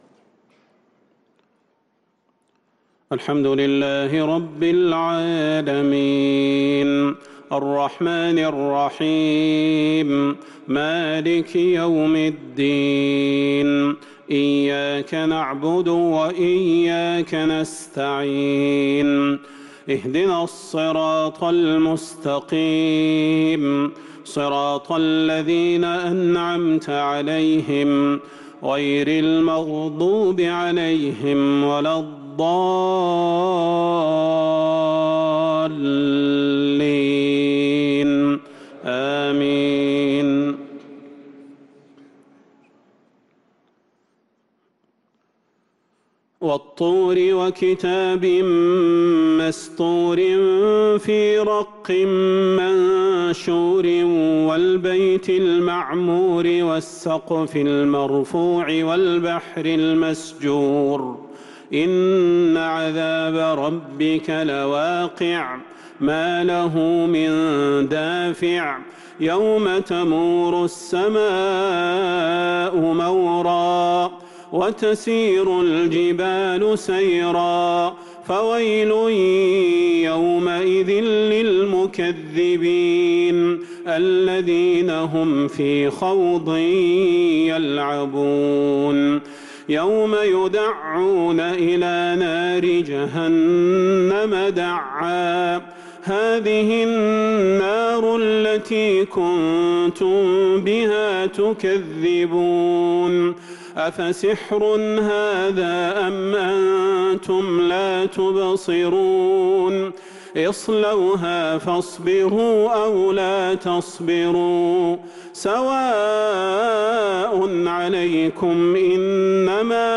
صلاة العشاء للقارئ صلاح البدير 15 شوال 1443 هـ
تِلَاوَات الْحَرَمَيْن .